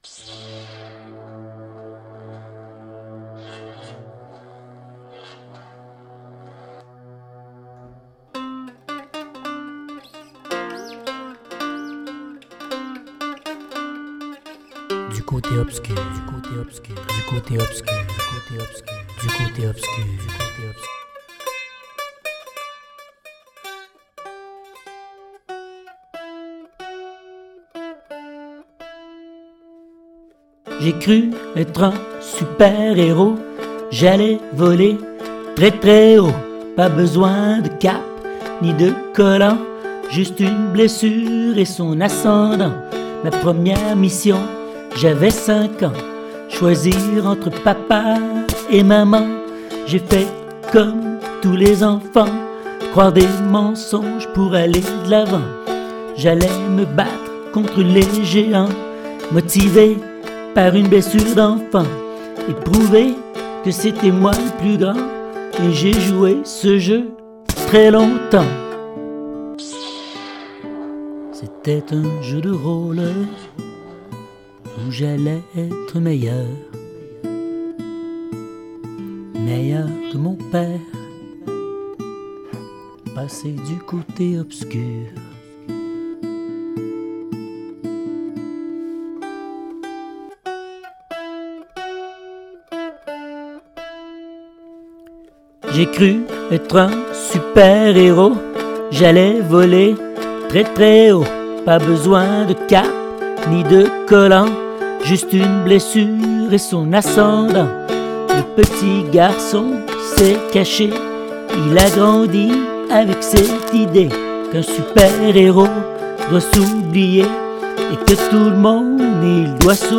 Comme le sujet est plus « lourd », je cherchais une musique qui pouvait contrebalancer le texte.
p.s.: j’ai oublié de remercier mon chien, qui a contribué à l’enregistrement. Elle a décidé de bouger alors qu’avais j’une prise parfaite, j’ai décidé de la garder.